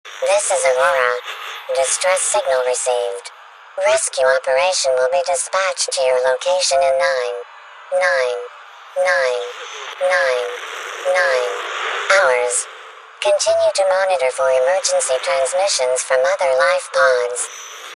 RadioAuroraDistressSignal.ogg